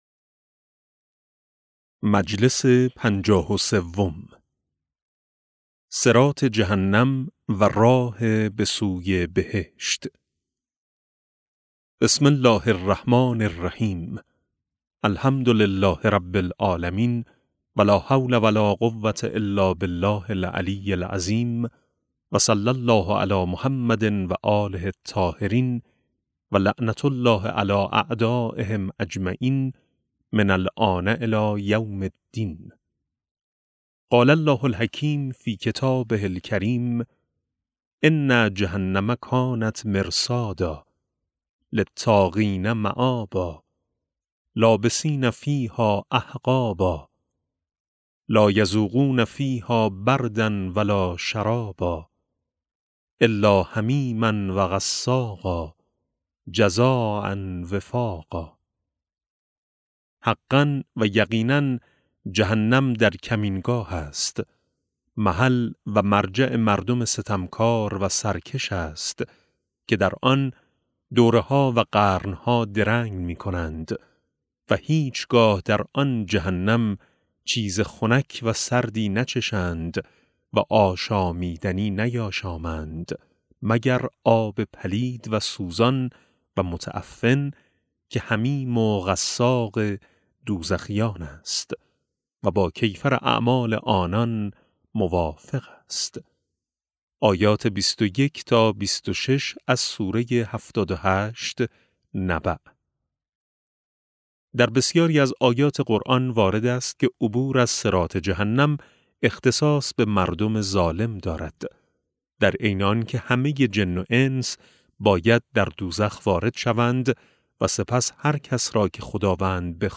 کتاب صوتی معاد شناسی ج8 - جلسه3